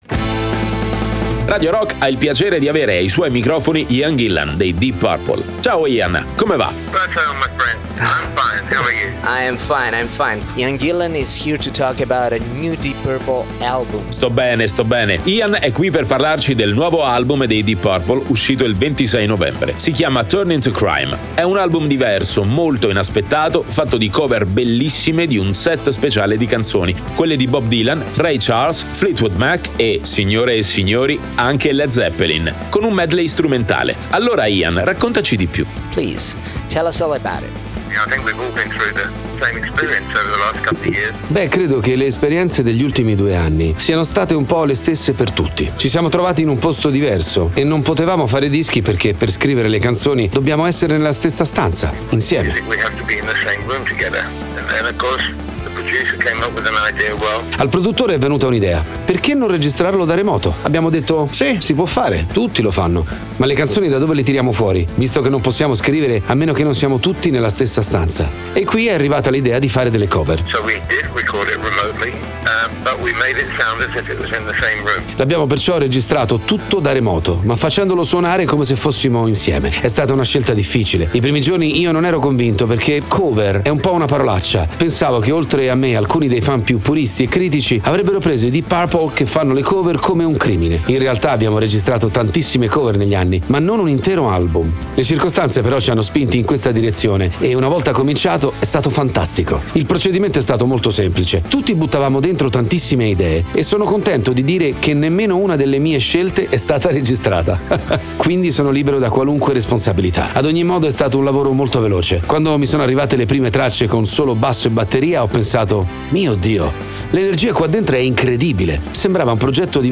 intervista Ian Gillan